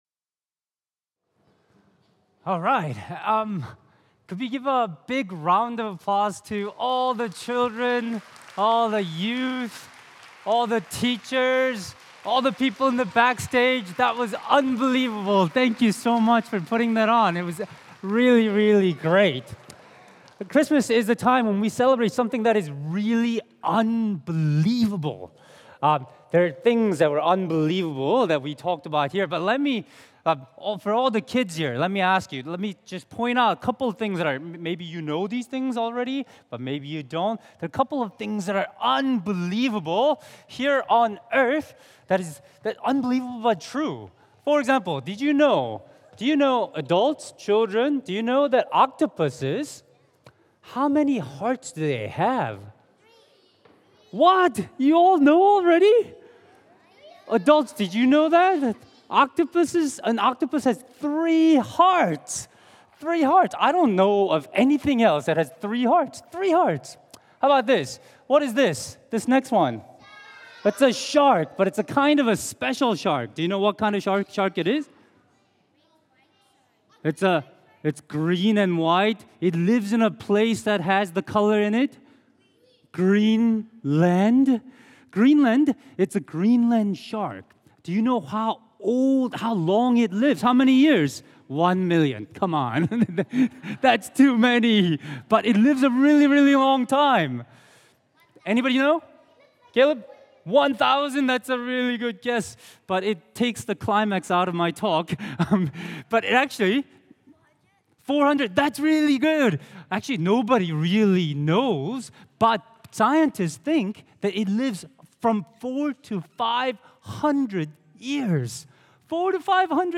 Family Christmas Service – Unbelievable Christmas – Shatin Anglican Church